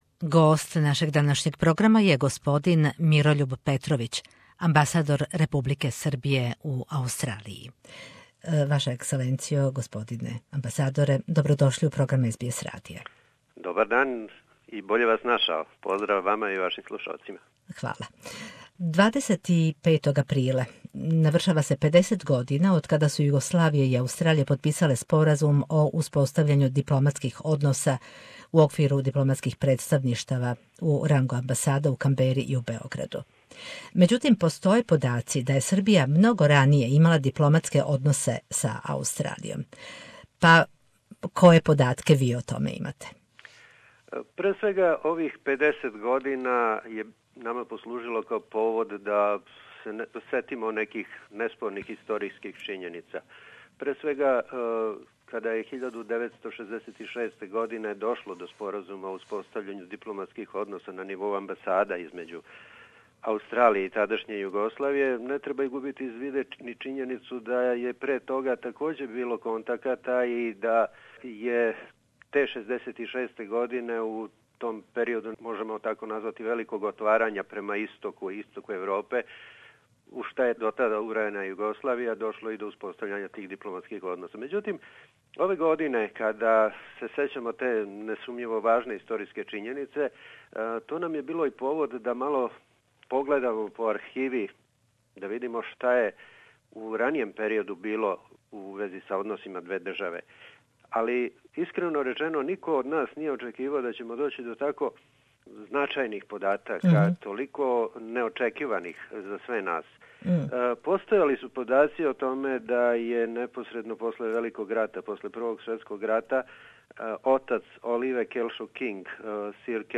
Поводом Дана Анзака, амбасадор Републике Србије у Аустралији говори ѕа СБС радио о новооткривеним подацима о дугогодишњој дипломатској сарадњи две земље.